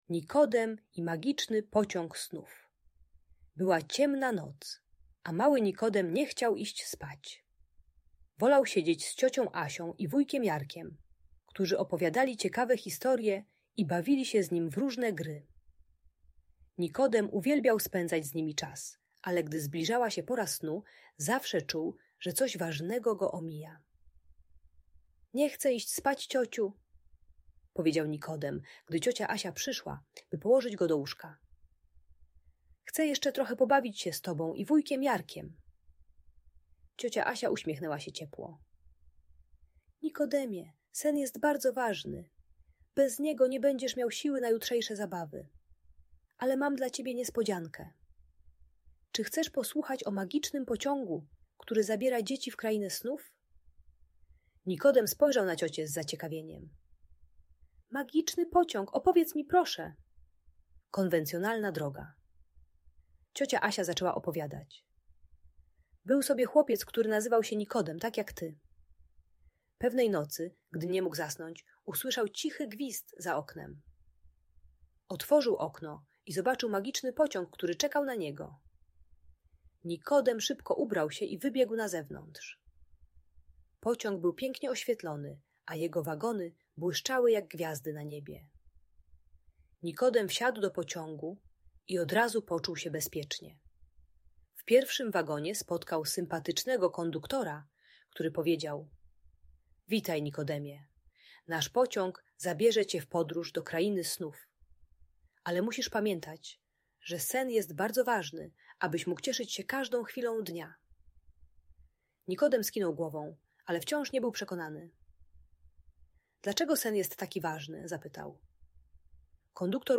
Nikodem i magiczny pociąg snów - Audiobajka